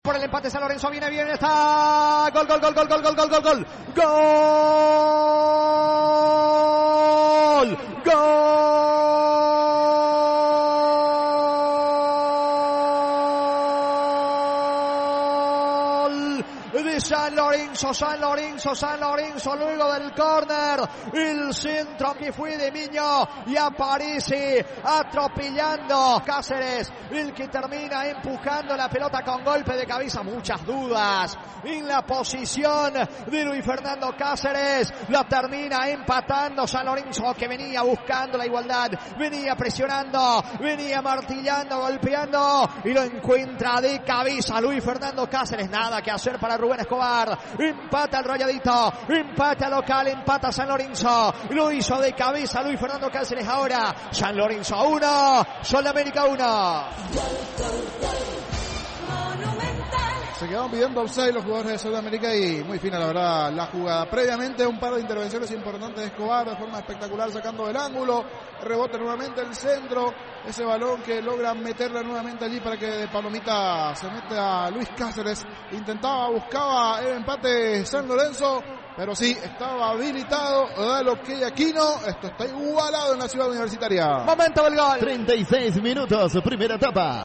Relatos y comentarios del equipo de Fútbol a lo Grande.